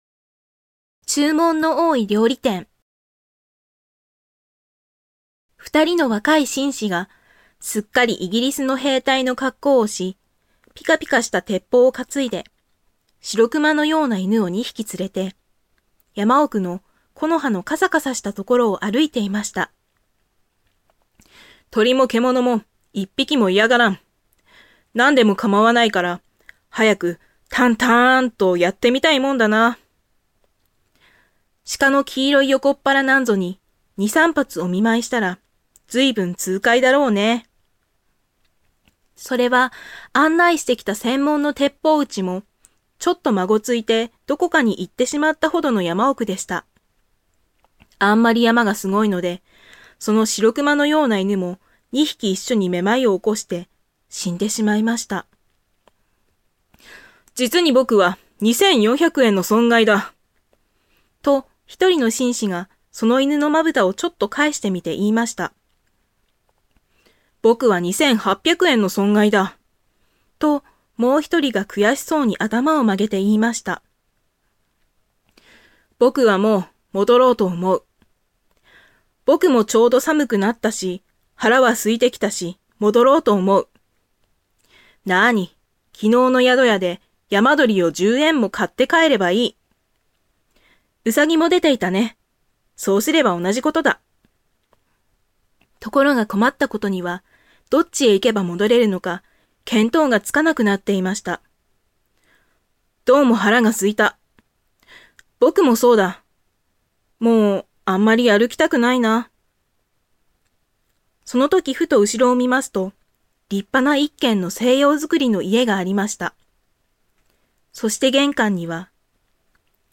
追記にてちょっとだけ朗読してみましたお付き合いくださる方はよろしくどうぞ(音量注意)
イーハトーヴ民として見た瞬間これは買わなきゃと思って 衝動買いしたフェリシモのボディークリーム(*ΦωΦ*) しかもミルクの香りという芸の細かさ笑 カサカサするであろうこれからの季節、活躍してもらう予定です 追記にてちょっとだけ朗読してみました お付き合いくださる方はよろしくどうぞ (音量注意) ※ド素人による朗読です。